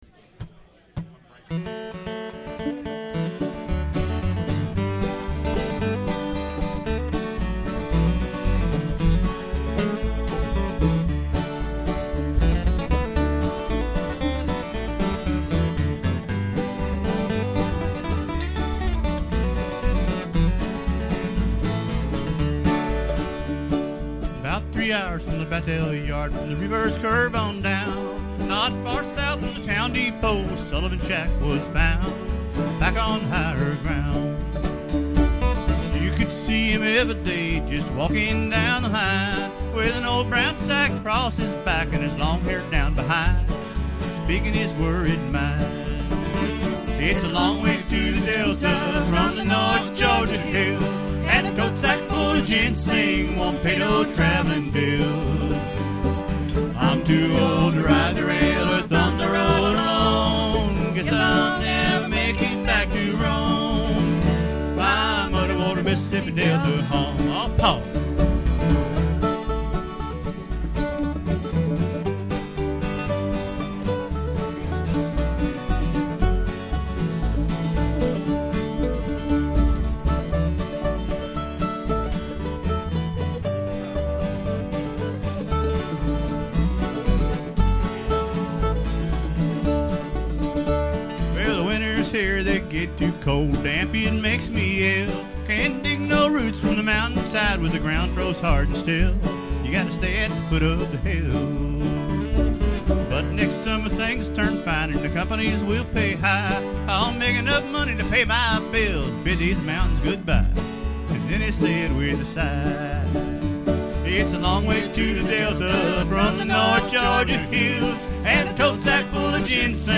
Американа со вкусом кантри / мятлика ...